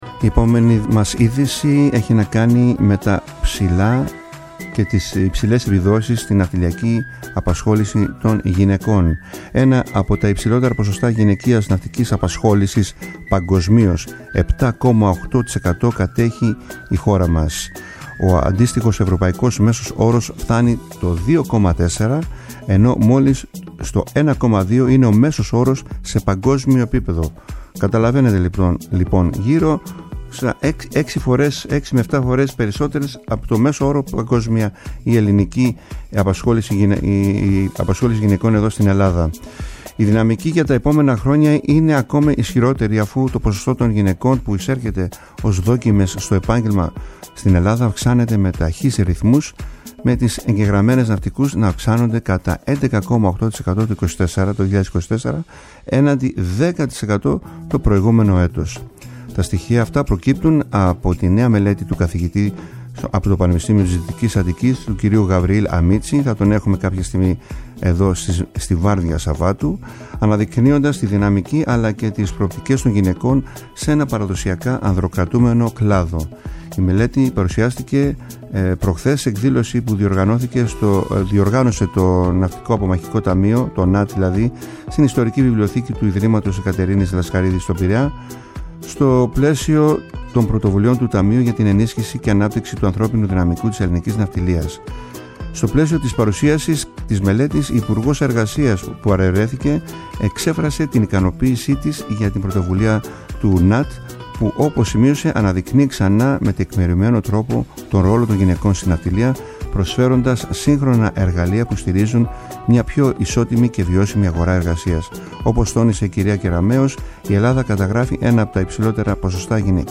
Ακούστε τις δηλώσεις – ομιλία της υπουργού Εργασίας, κυρίας Νίκης Κεραμέως για την παρουσίαση της μελέτης – βιβλίου του καθηγητή του Πανεπιστημίου Δυτικής Αττικής Γαβριήλ Αμίτση με πρωτοβουλία του ΝΑΤ.
Η μελέτη παρουσιάστηκε την Τρίτη 25 Νοεμβρίου, σε εκδήλωση που διοργάνωσε το ΝΑΤ στην Ιστορική Βιβλιοθήκη του Ιδρύματος Αικατερίνης Λασκαρίδη στον Πειραιά, στο πλαίσιο των πρωτοβουλιών του Ταμείου για την ενίσχυση και ανάπτυξη του ανθρώπινου δυναμικού της ελληνικής ναυτιλίας και οι «ΚΑΛΕΣ ΘΑΛΑΣΣΕΣ» ήταν εκεί και κατέγραψαν το γεγονός.